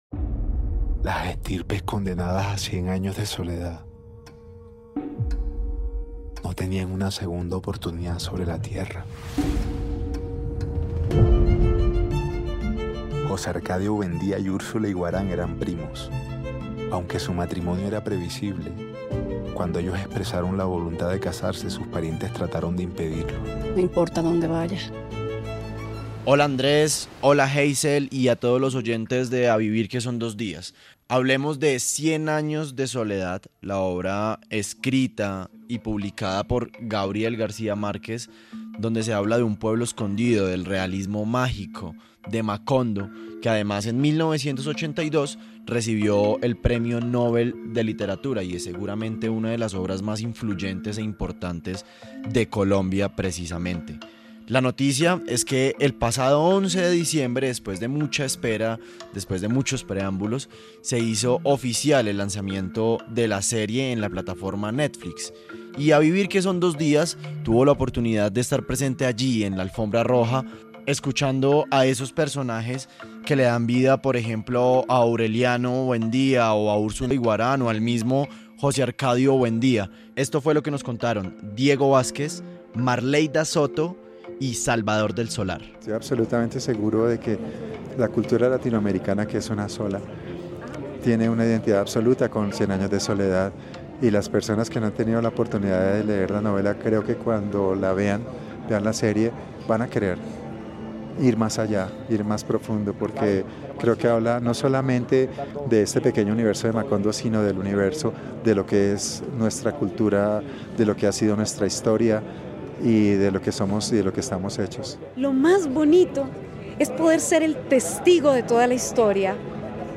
´A Vivir Que Son Dos Días´ tuvo la oportunidad de hablar con algunos del elenco de la cinta presentada por Netflix, quienes, además, mencionaron que, a pesar de hablar sobre el universo de Macondo, también se habla de lo que es la cultura y la historia colombiana.